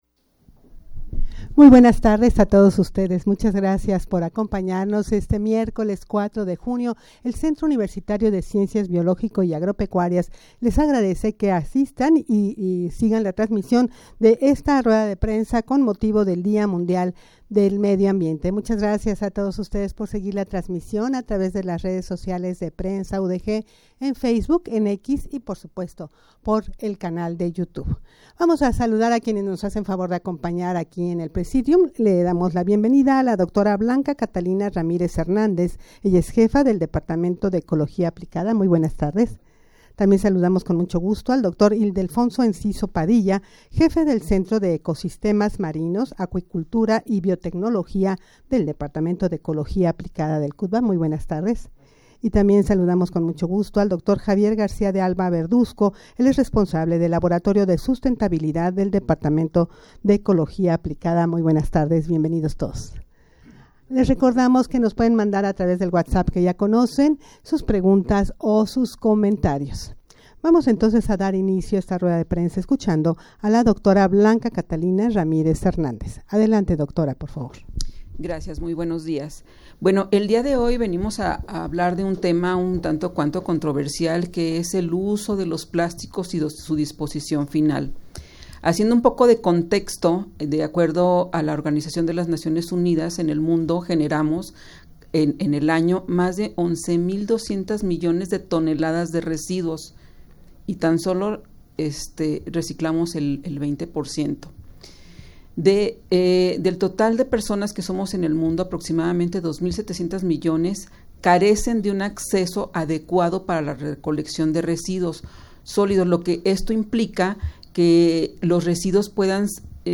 Audio de la Rueda de Prensa
rueda-de-prensa-con-motivo-del-dia-mundial-del-medio-ambiente.mp3